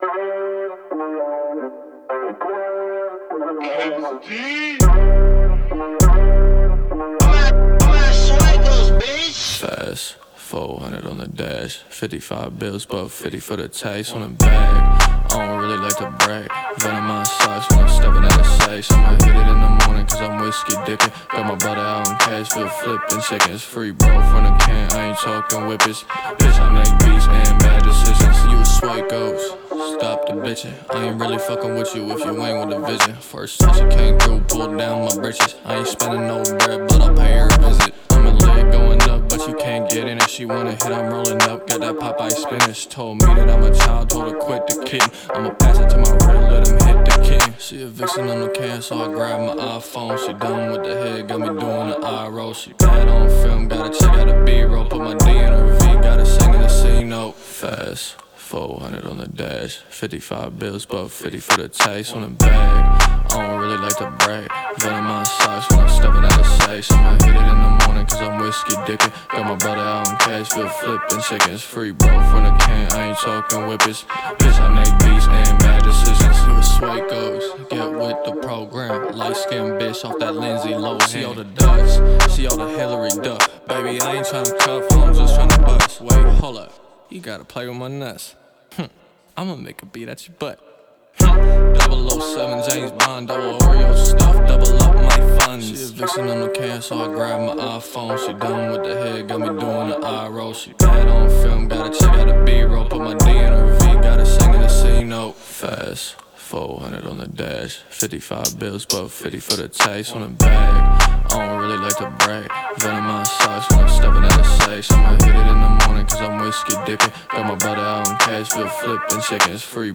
динамичными битами и запоминающимся мелодичным припевом